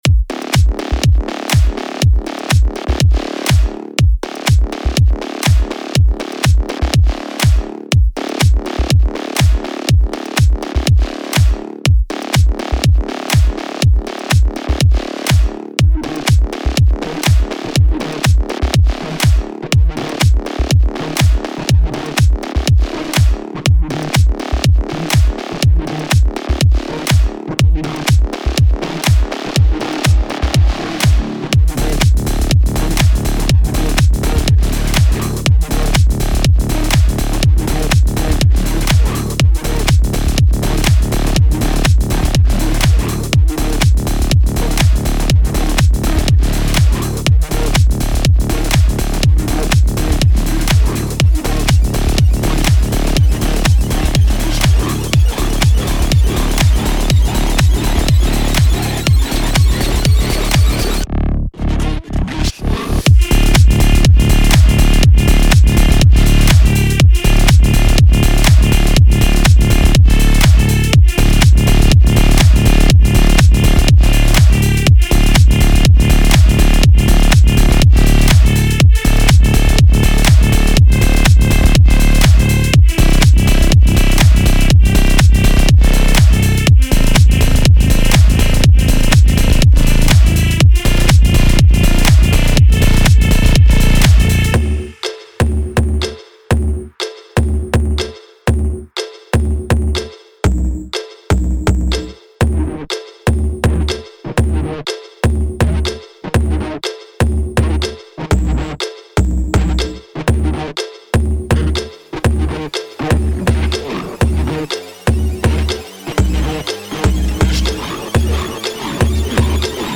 BASE ACOMPAÑAMENTO 2